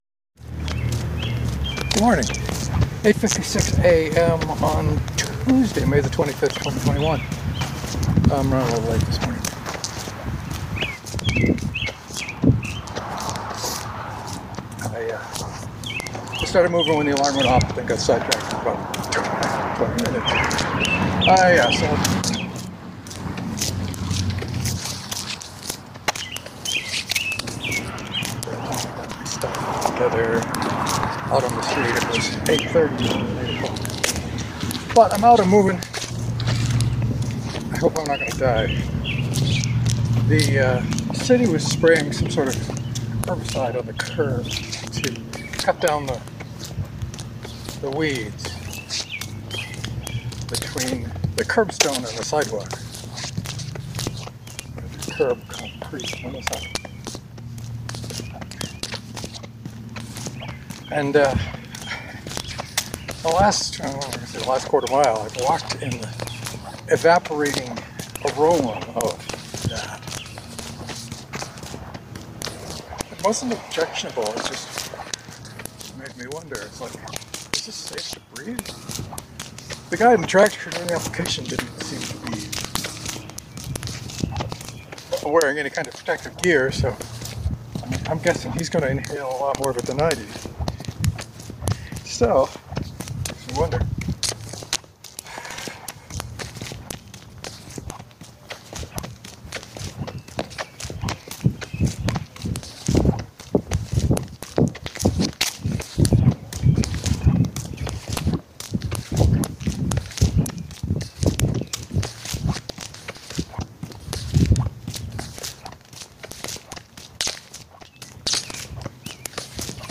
FILE WARNING: The sound quality today is bad. I don’t think I had the mic plugged all the way in (again). The recording all comes from the phone in my hand.